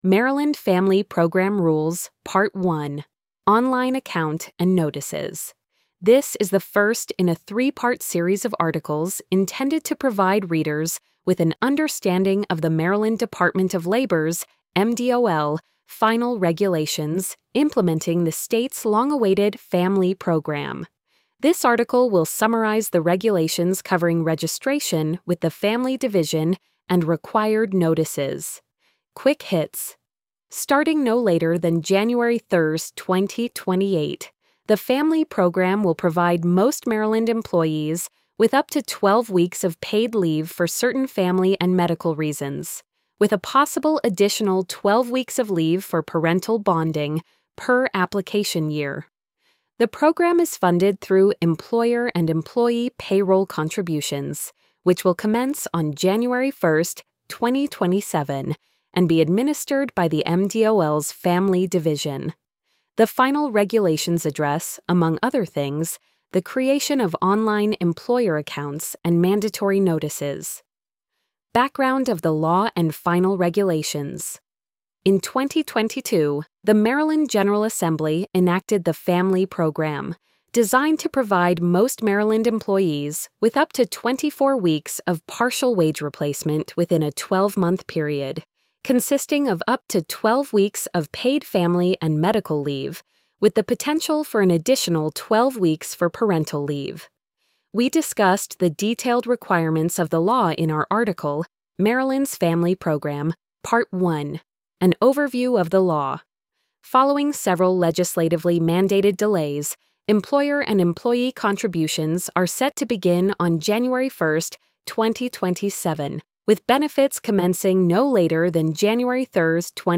post-87366-tts.mp3